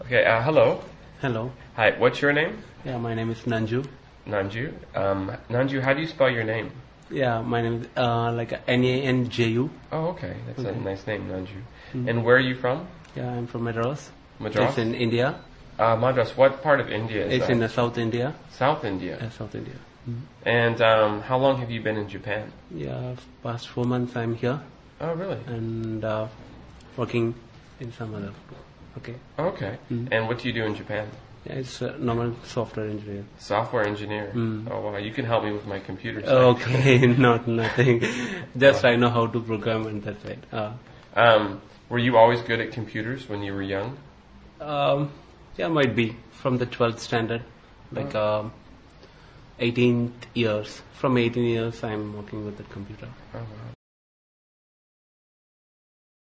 英语初级口语对话正常语速01